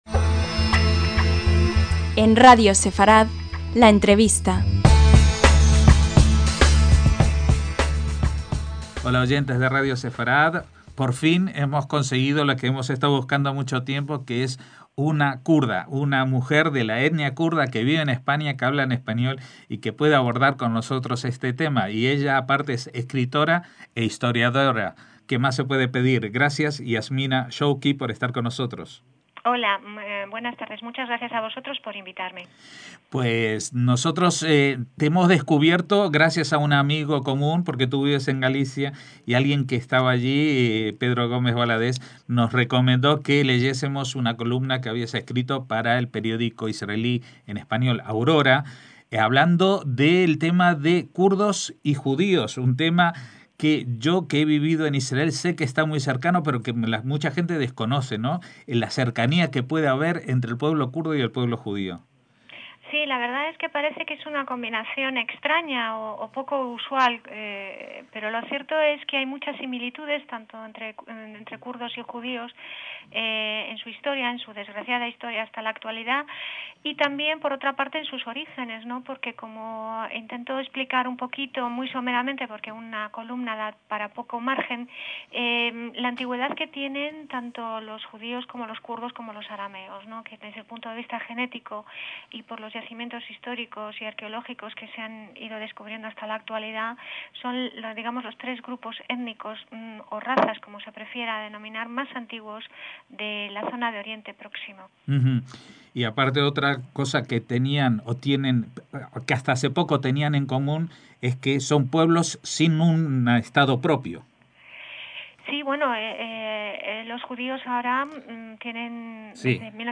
LA ENTREVISTA